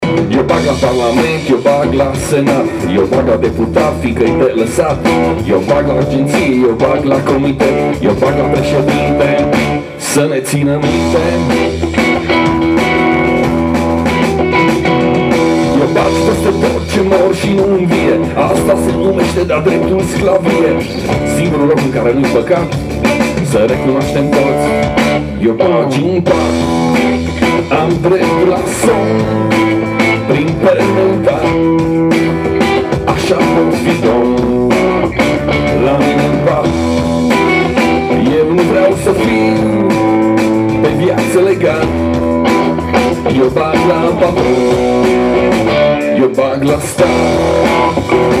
chitară bas
clape
voce și chitară.